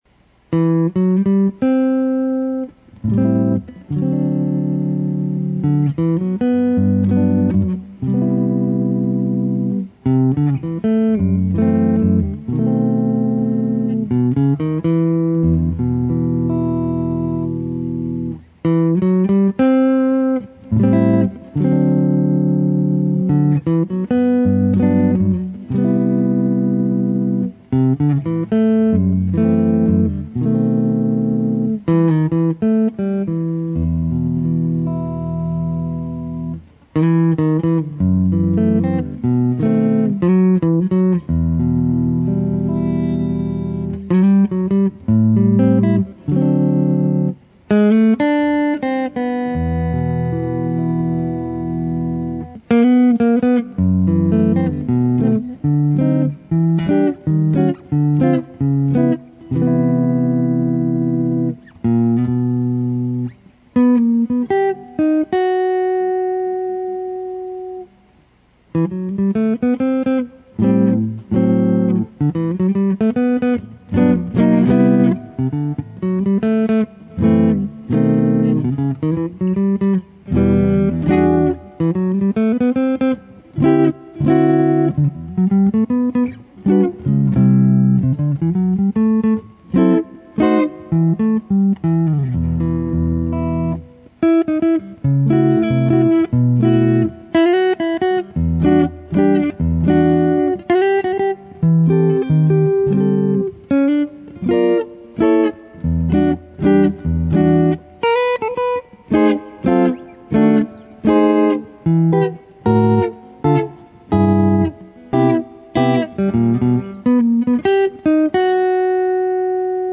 Jazz Standards
Jazz chord solos are my favorite kind of guitar music.